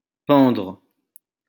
wymowa:
(1) IPA/pɑ̃dʁ/